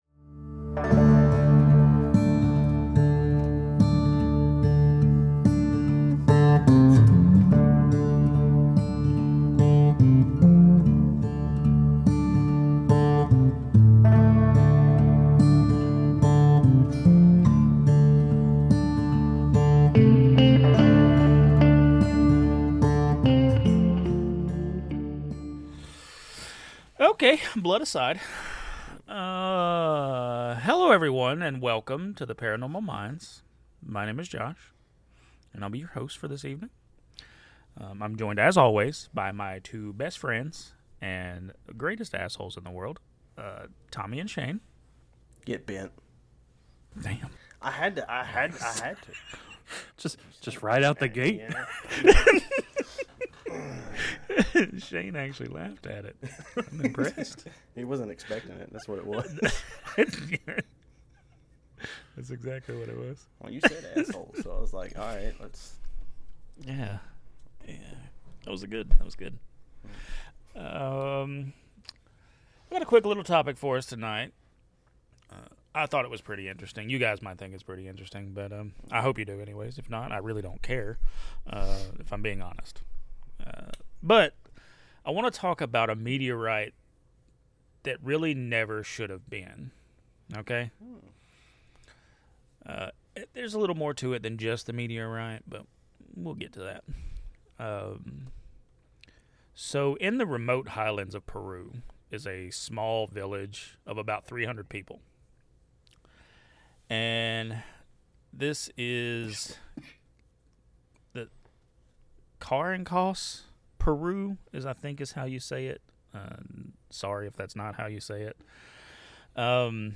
Friendly banter!?!?!